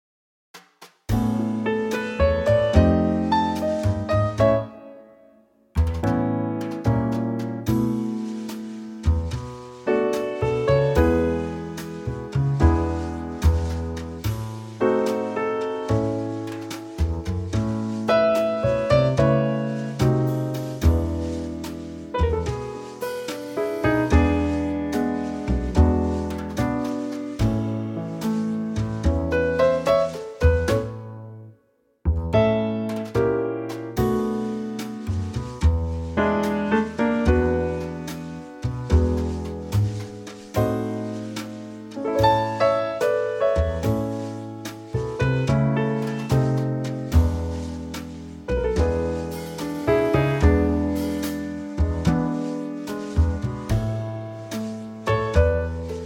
Unique Backing Tracks
key - A - vocal range - A to B
Here's a lovely Trio arrangement.